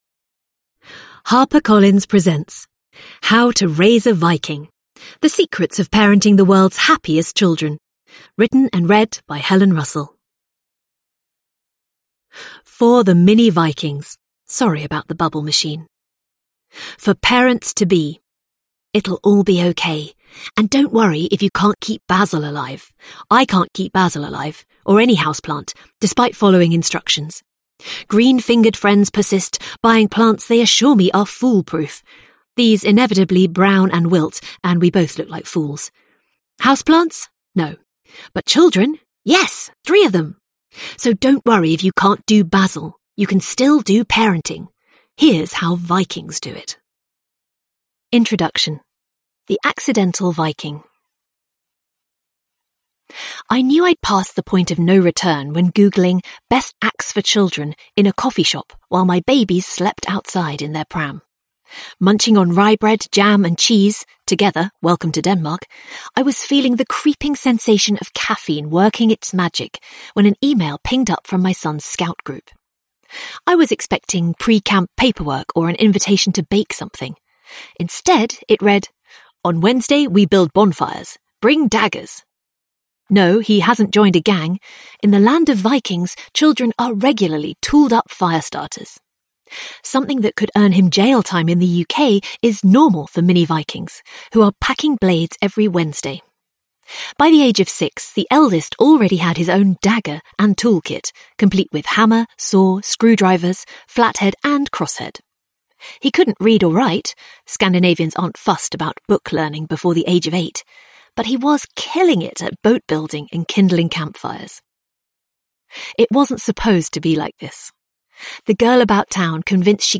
The Danish Secret to Happy Kids – Ljudbok
Uppläsare: Helen Russell